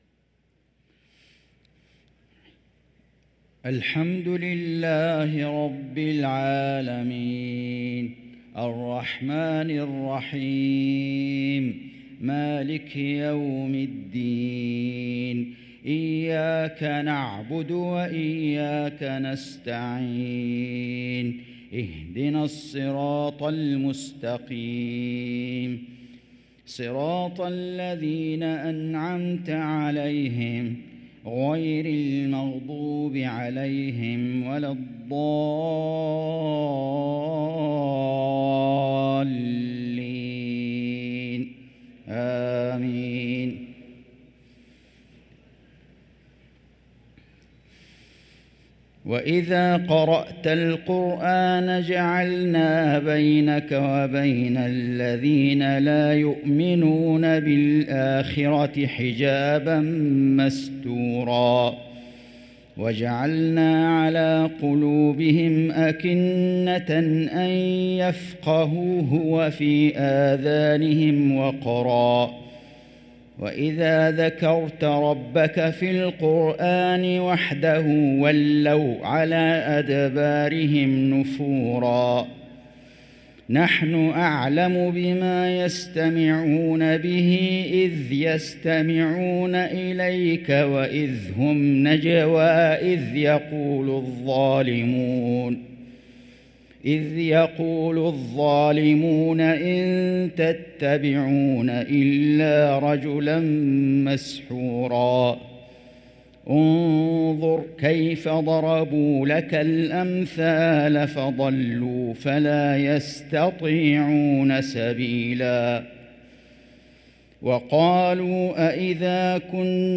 صلاة العشاء للقارئ فيصل غزاوي 1 صفر 1445 هـ
تِلَاوَات الْحَرَمَيْن .